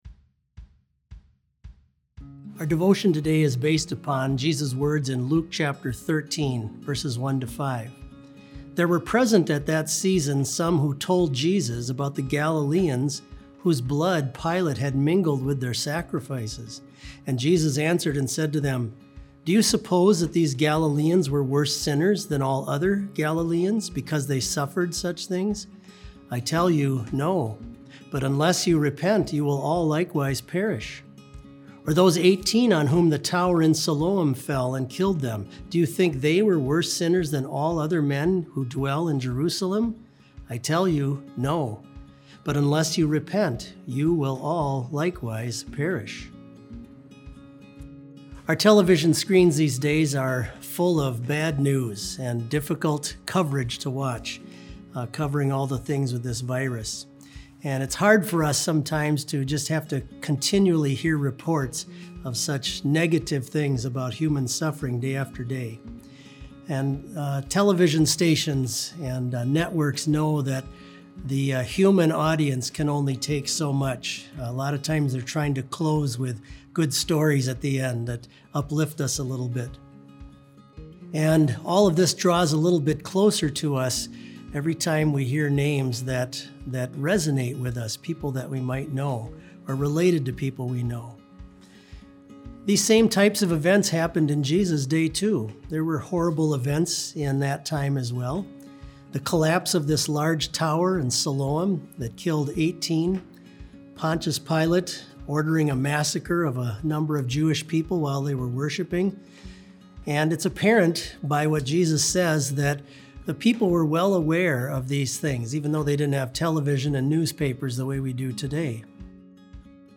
Complete service audio for BLC Devotion - April 13, 2020